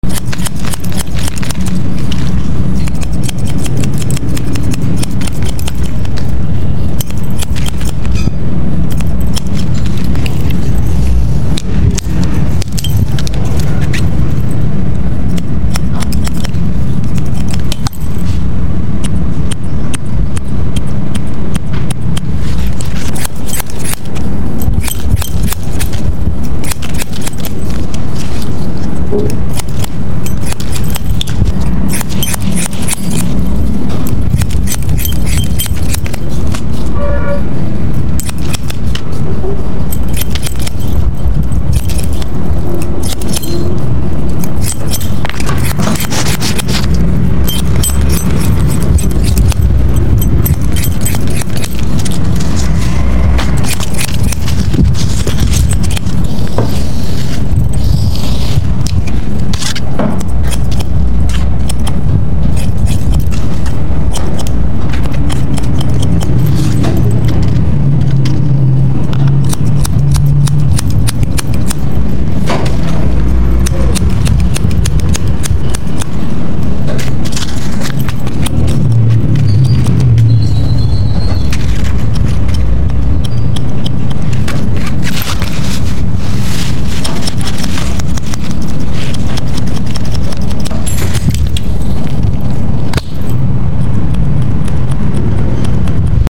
ASMR Oddly Satisfying Sounds | Sound Effects Free Download
ASMR Oddly Satisfying Sounds | Relaxing Haircuts